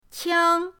qiang1.mp3